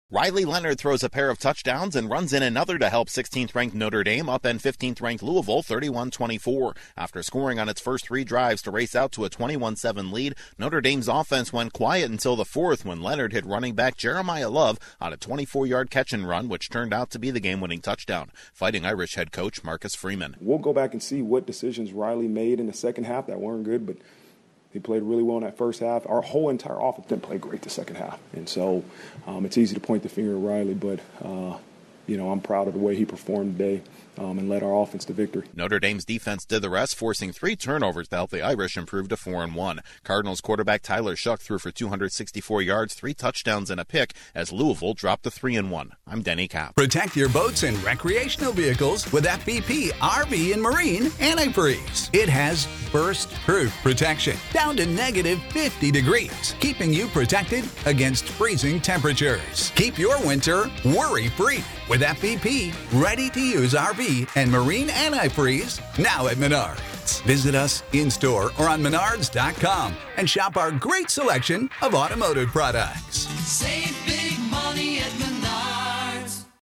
Notre Dame upsets Louisville and deals the Cardinals their first loss of the season. Correspondent